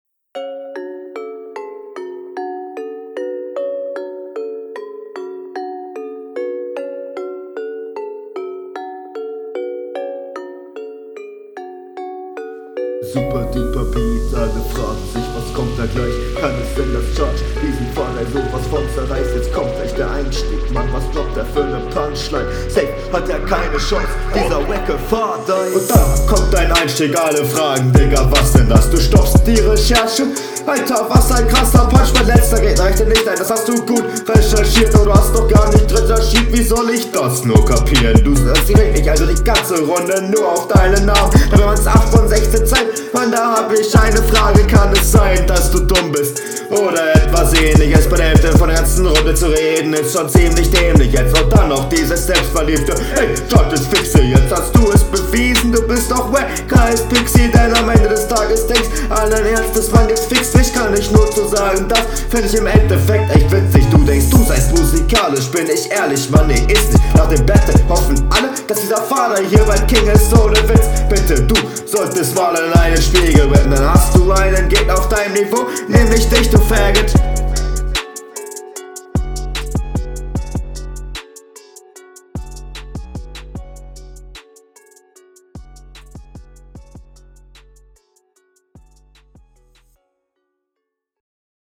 Flow: Flow besser als in der HR, aber leider immernoch oft sehr offbeat.
Flow: Flow ist okay, triffst fast immer den Takt Text: meh, reime sind kurzgesagt einfach …